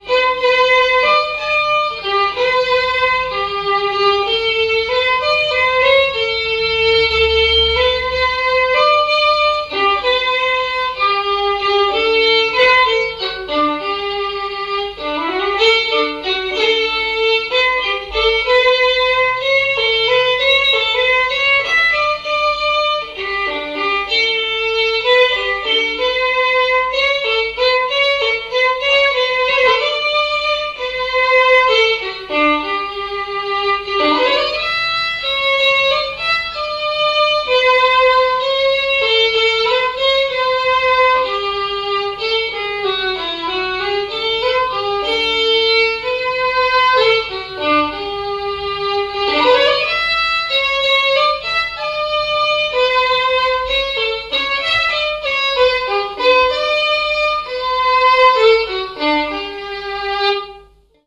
Fonction d'après l'analyste danse : valse
Genre strophique
répertoire de bals et de noces
Pièce musicale inédite